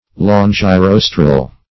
Search Result for " longirostral" : The Collaborative International Dictionary of English v.0.48: Longirostral \Lon`gi*ros"tral\, a. (Zool.)